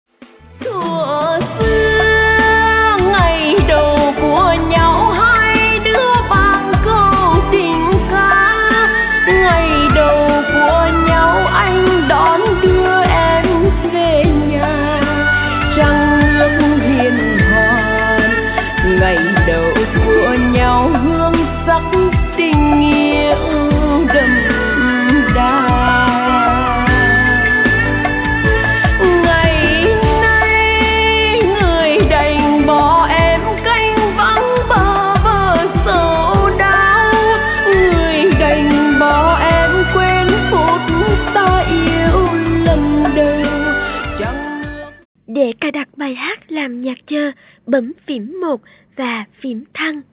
Bolero/ Trữ tình